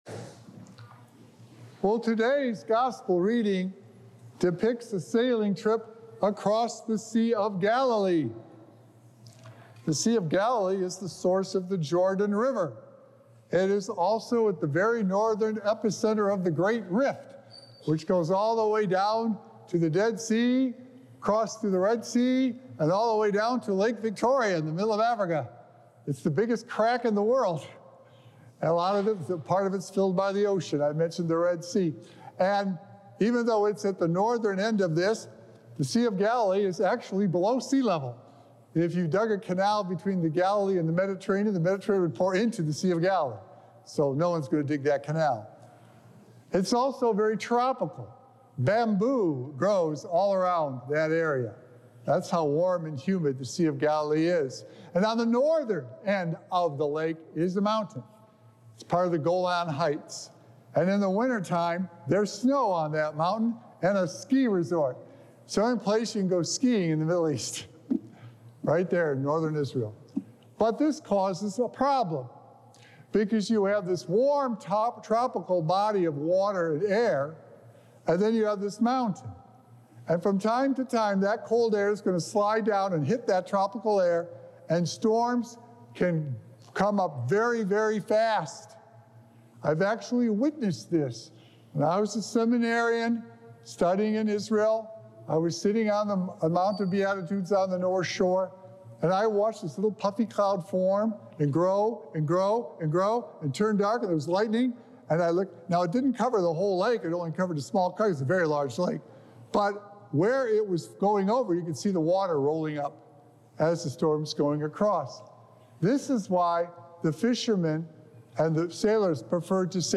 Recorded Live on Sunday, June 23rd, 2024 at St. Malachy Catholic Church.
Weekly Homilies